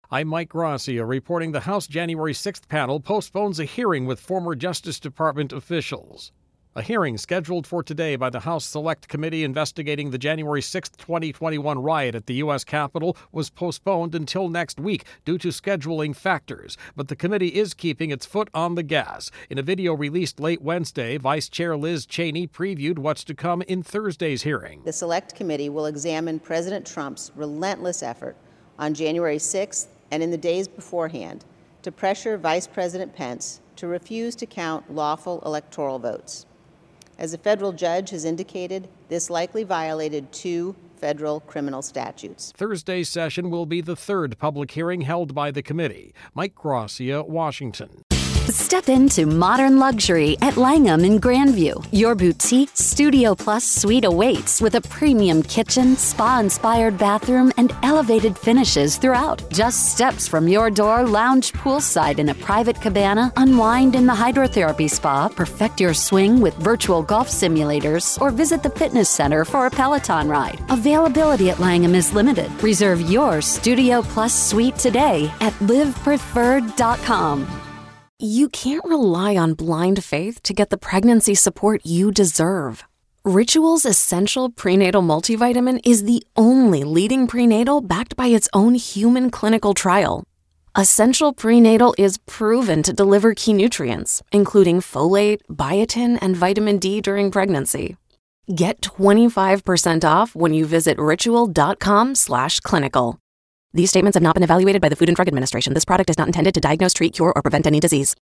Wednesday a.m. wrap